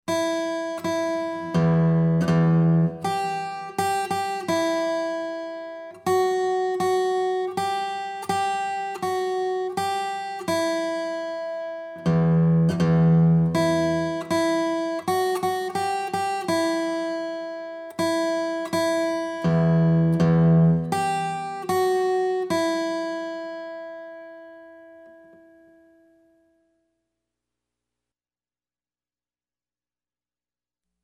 Rock Freebies